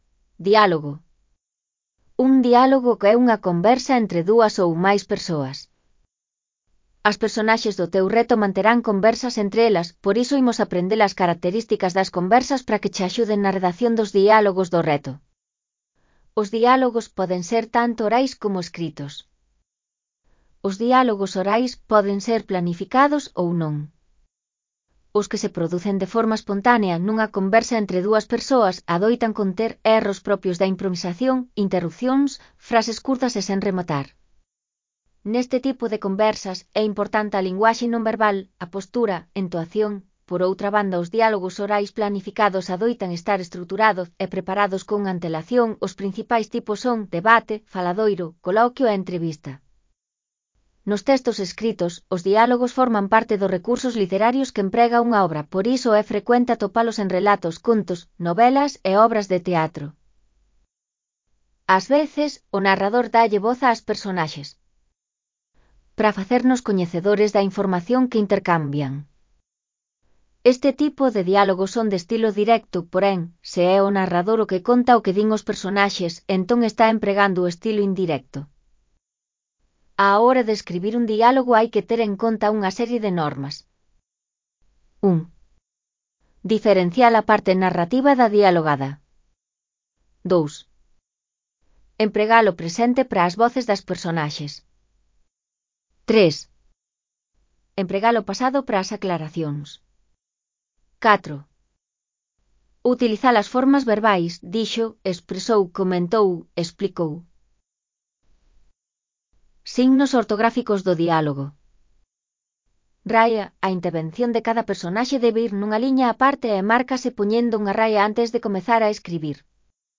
Exemplo de diálogo oral
Dialogo_completo.mp3